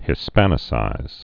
(hĭ-spănĭ-sīz)